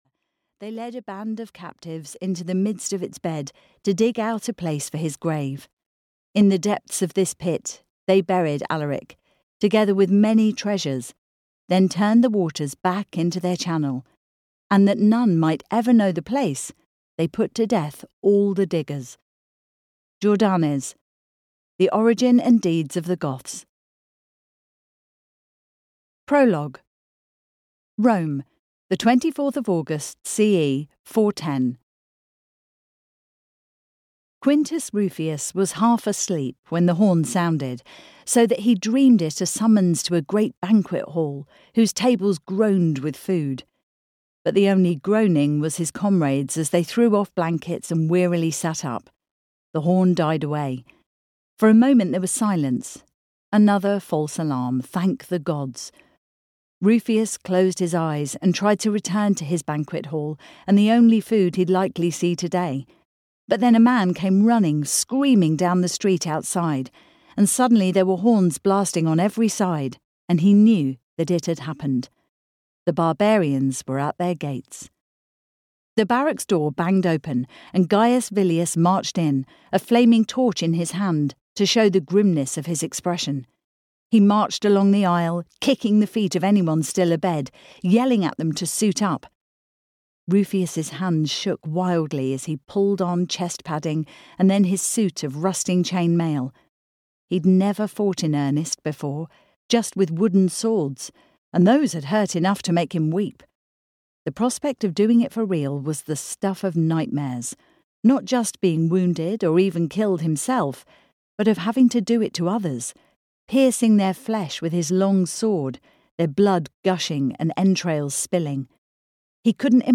The Sacred Spoils (EN) audiokniha
Ukázka z knihy